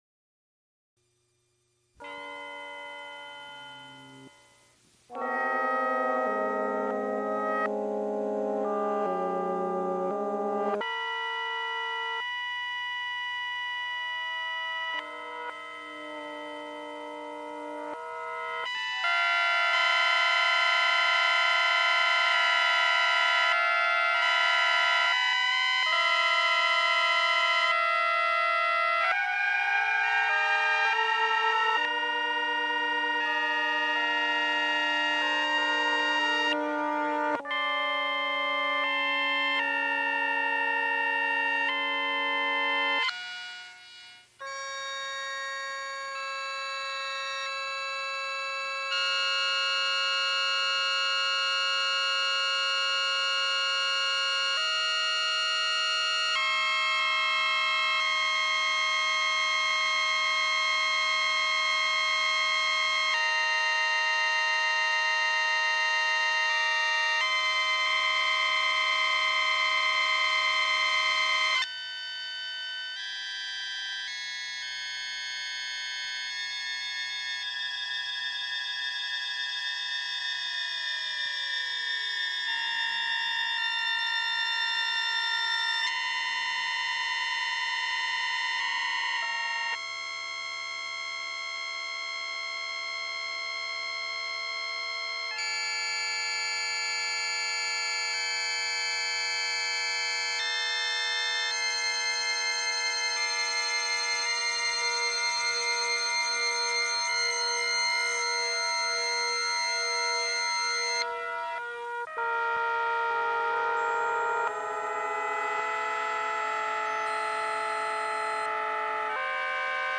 [Computer and electronic music concert] | Digital Pitt
Recorded live January 24, 1984, Frick Fine Arts Auditoruium, University of Pittsburgh.
musical performances
Computer music Electronic music Music--20th century